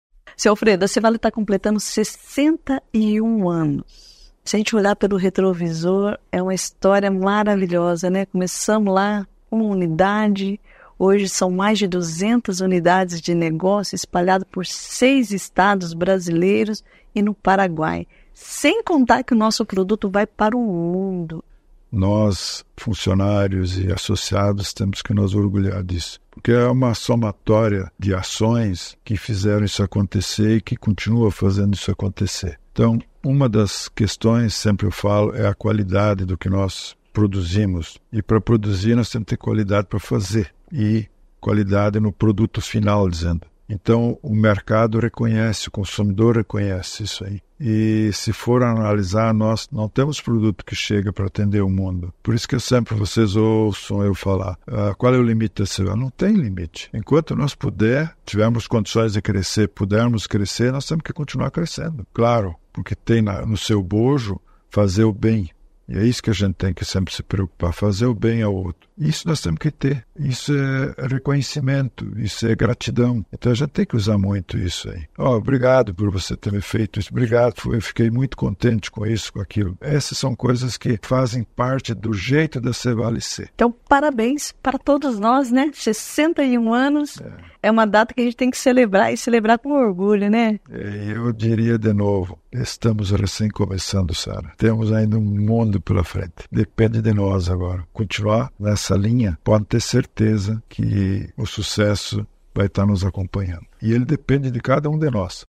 Reportagem – A agroindustrialização mudou a vida dos associados da C.Vale, gerando renda e emprego nas comunidades.